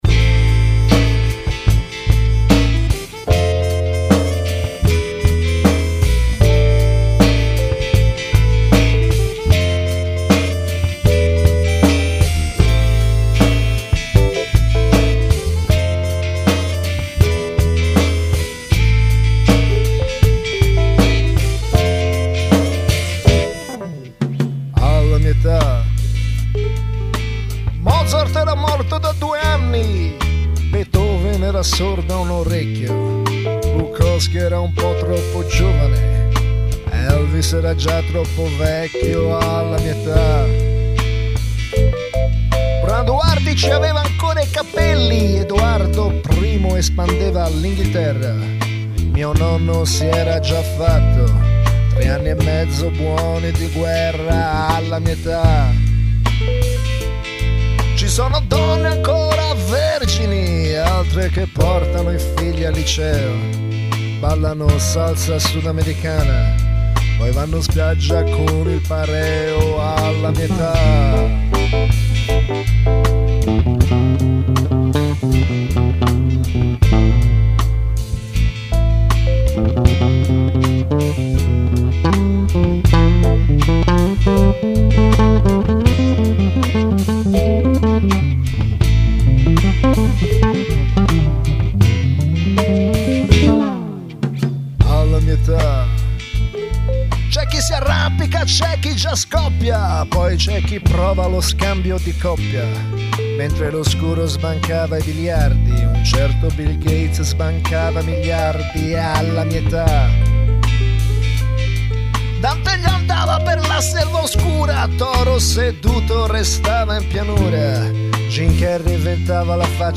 Un brano quasi serio e riflessivo